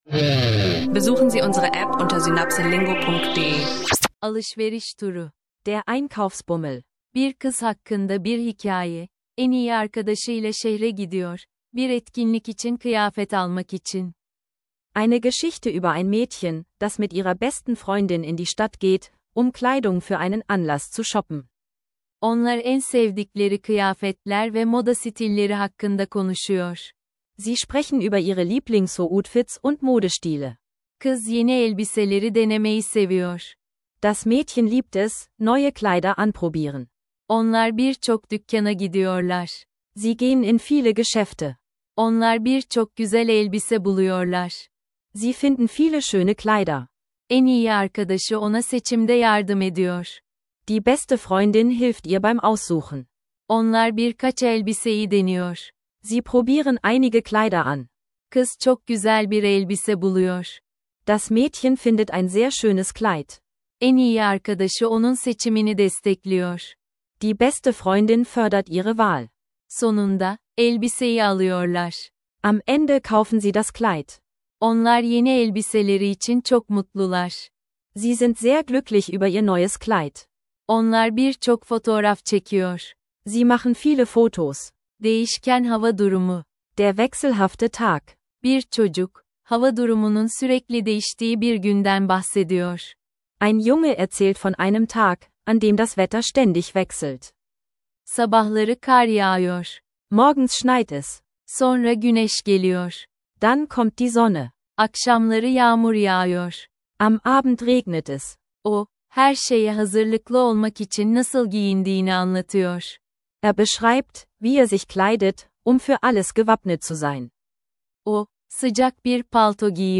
ein Mädchen und ihre Freundin beim Einkaufsbummel und erlernst